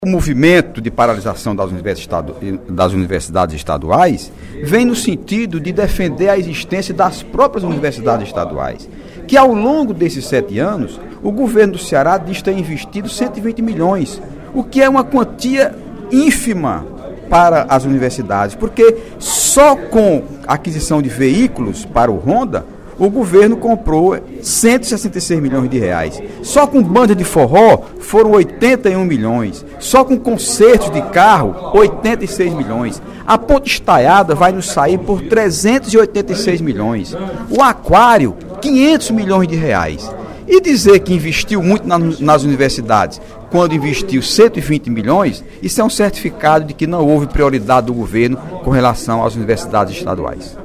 O deputado Heitor Férrer (PDT) afirmou, no primeiro expediente da sessão plenária desta quinta-feira (28/11), que o Governo tem tratado as universidades estaduais em greve (Uece, Urca e Uva) com desprestígio.